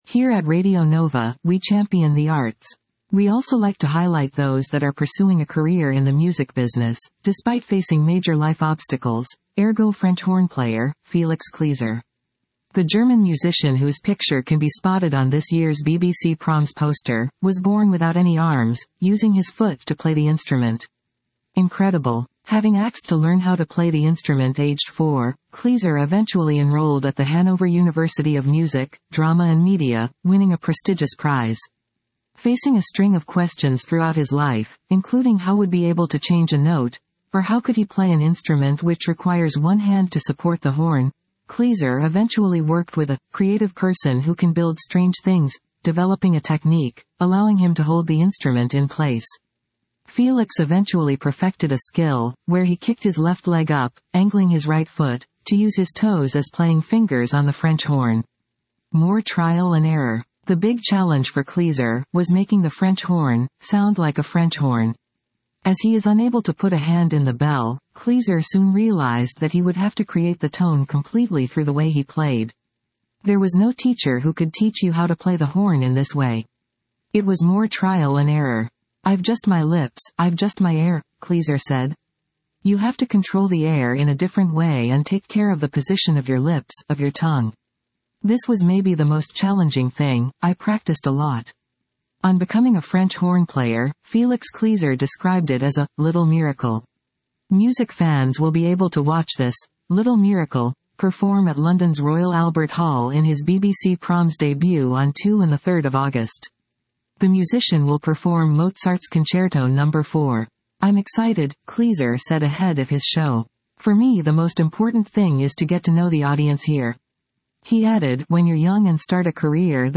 Check out the French Horn player Felix Klieser who will make his BBC Proms debut this week, despite being born with no arms.
The German musician whose picture can be spotted on this years’ BBC Proms Poster, was born without any arms, using his foot to play the instrument.